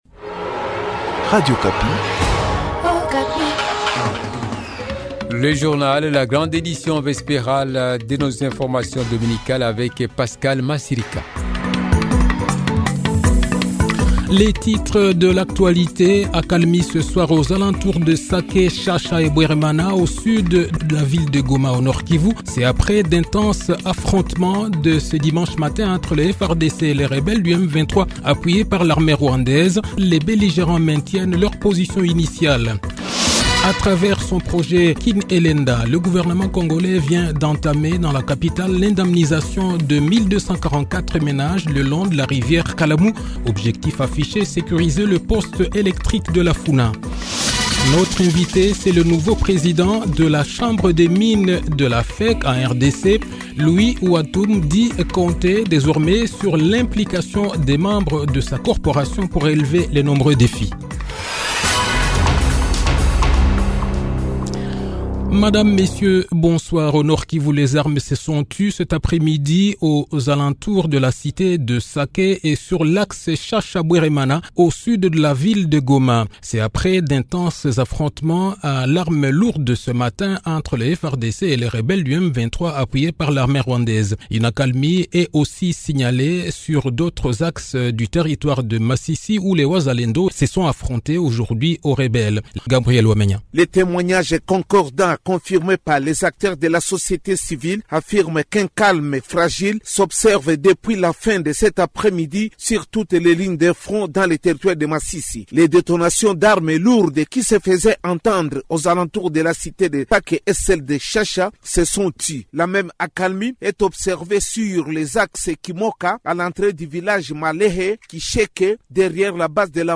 Le journal de 18 h, 25 fevrier 2024